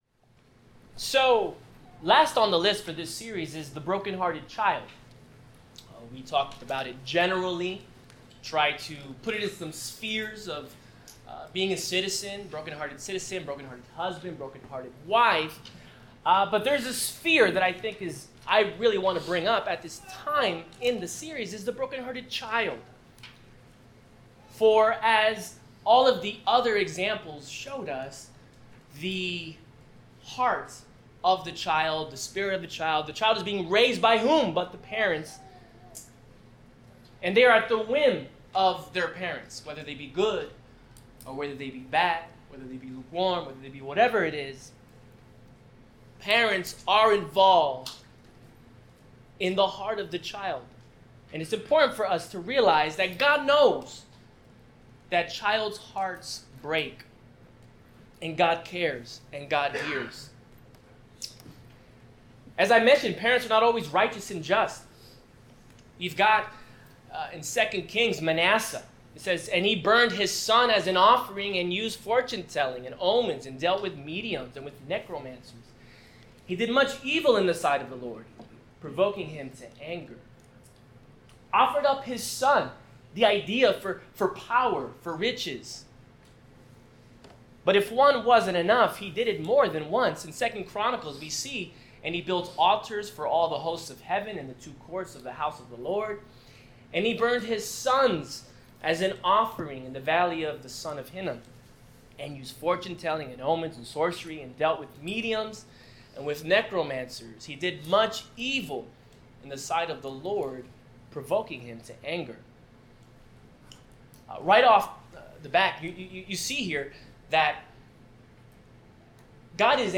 Series: God of the Brokenhearted Service Type: Sermon